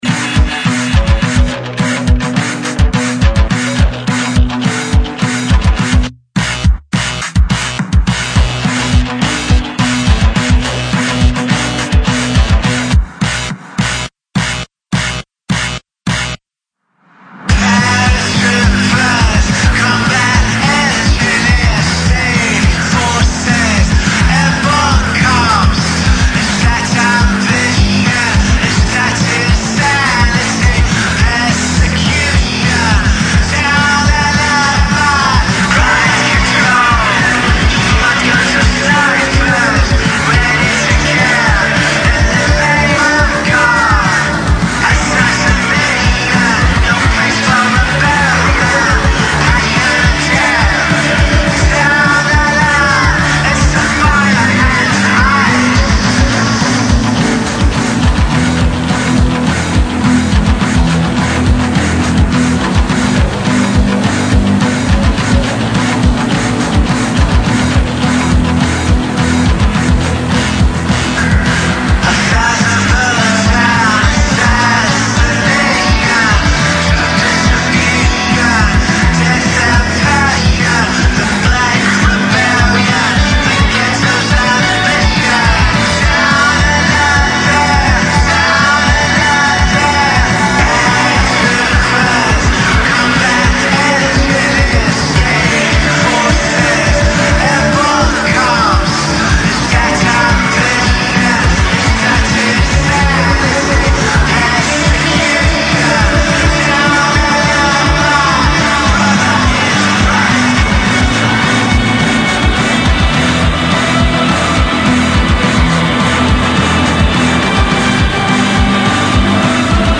Electro House